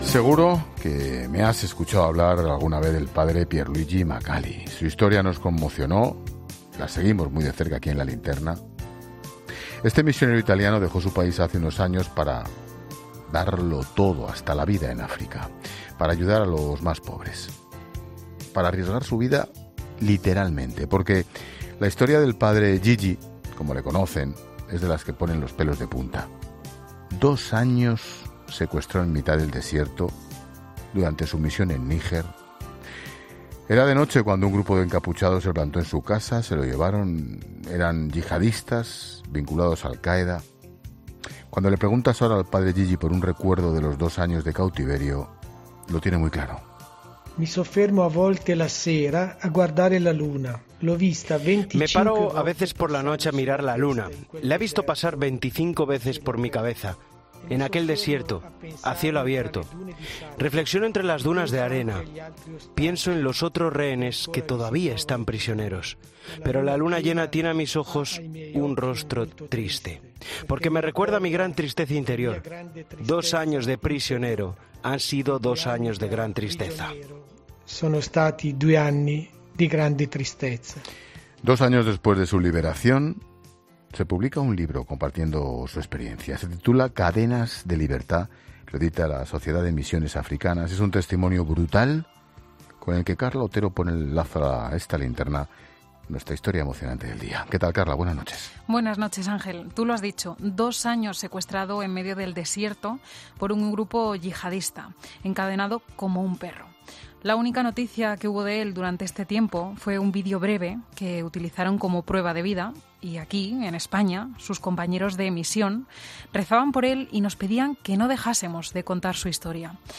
una historia que recuerda Ángel Expósito en La Linterna